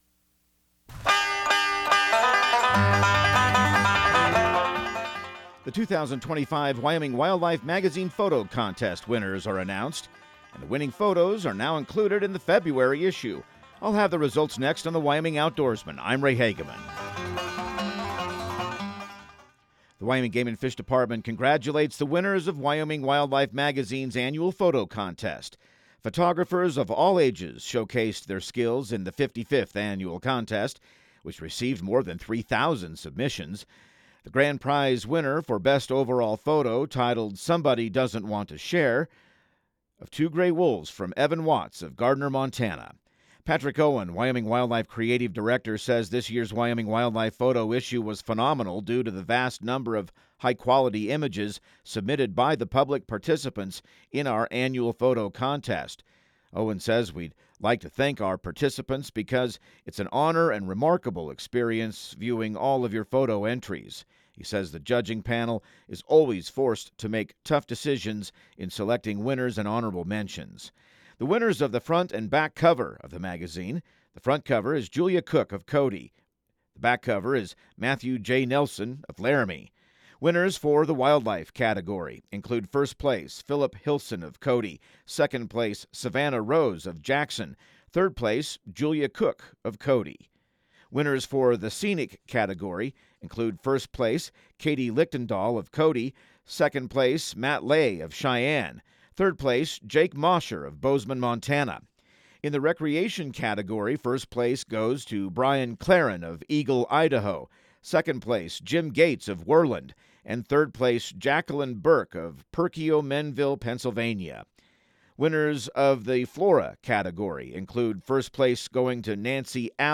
Radio news | Week of February 24